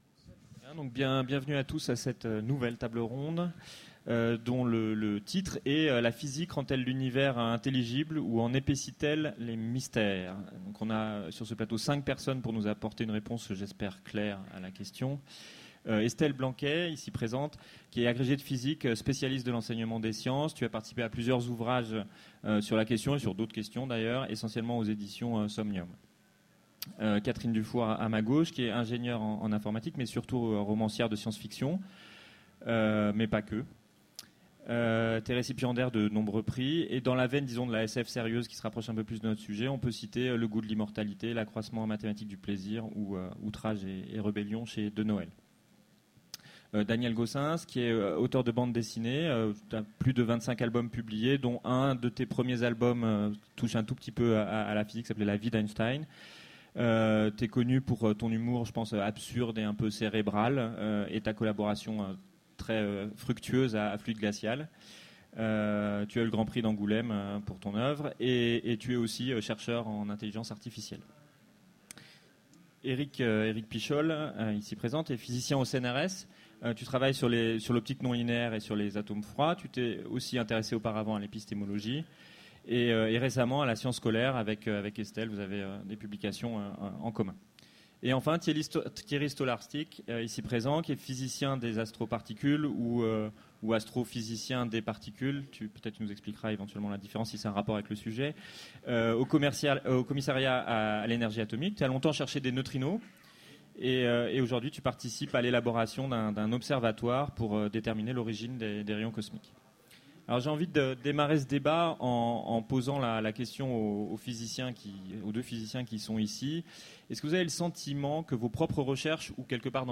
Mots-clés sciences Conférence Partager cet article